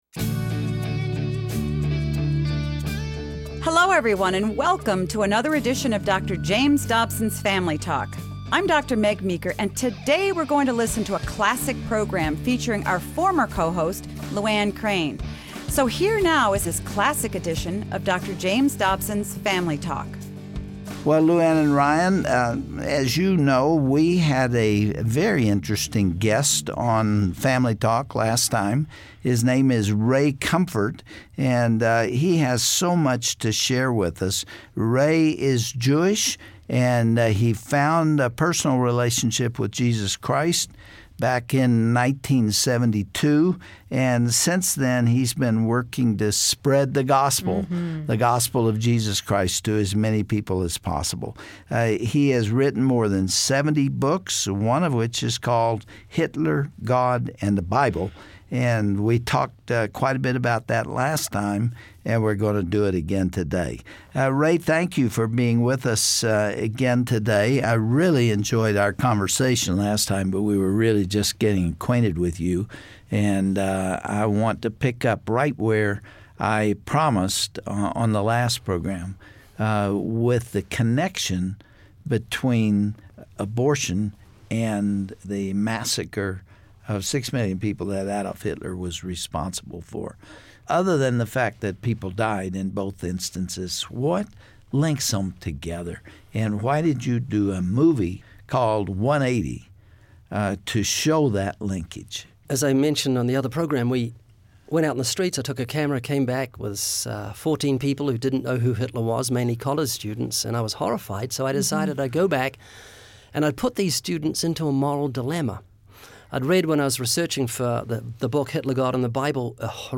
On the next edition of Family Talk, Dr. James Dobson interviews Ray Comfort regarding abortion as Americas Holocaust.